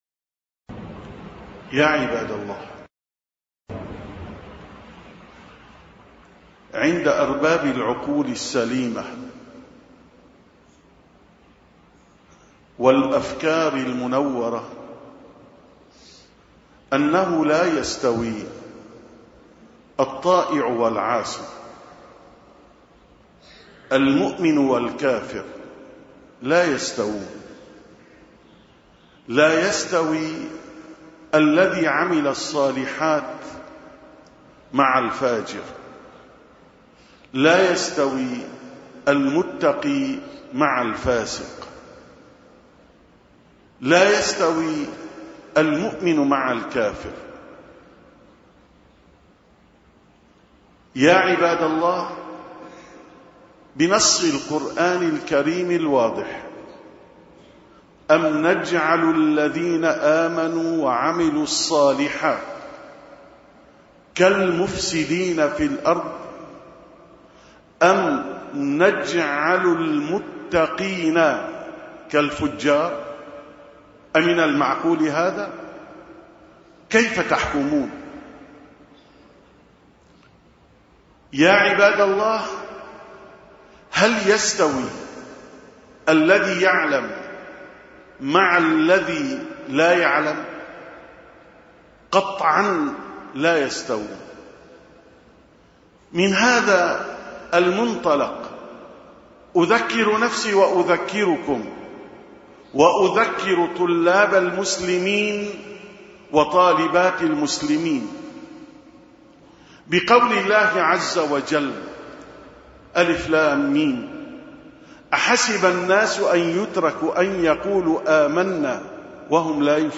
خطب الجمعة خطب الجمعة 874ـ خطبة الجمعة: احذروا الغش أيها الطلاب مقدمة الخطبة: الحمد لله رب العالمين، وأفضل الصلاة وأتم التسليم على سيدنا محمد، وعلى آله وصحبه أجمعين.